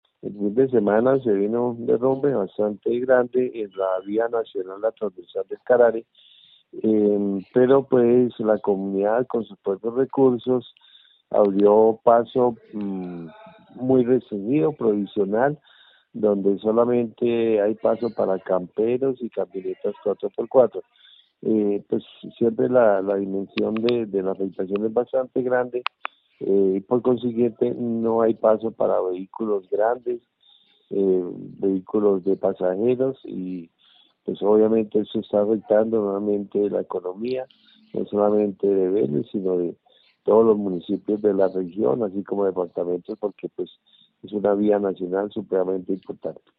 Alcalde de Vélez, Orlando Ariza